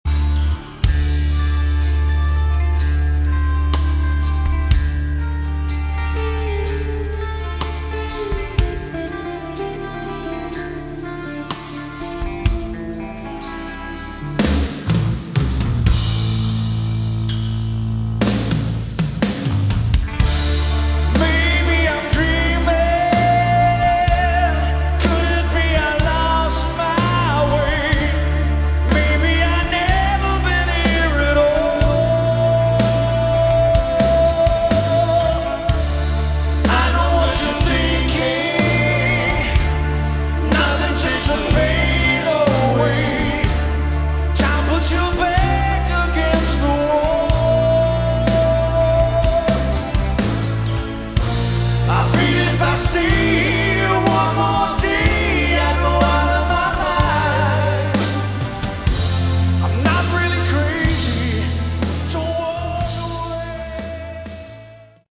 Category: Hard Rock
bass, vocals
guitar, vocals
drums, vocals
lead vocals